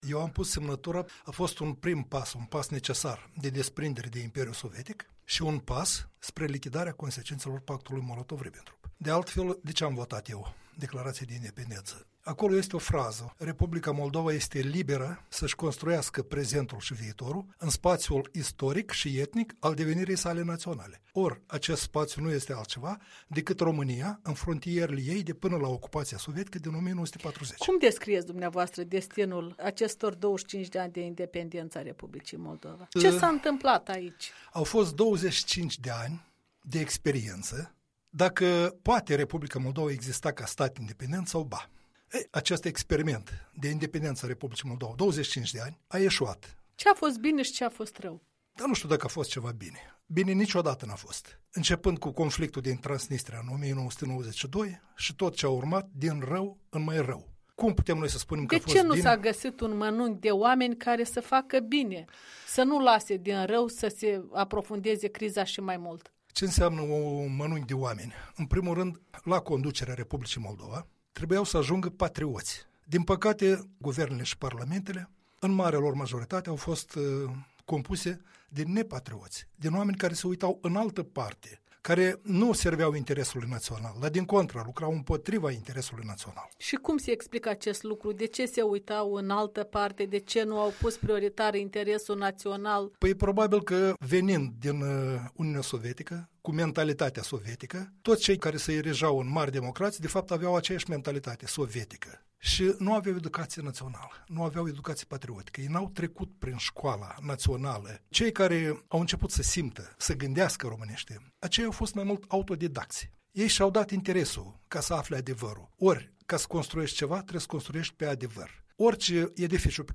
Interviu cu unul din semnatarii declarației de independență a Moldovei.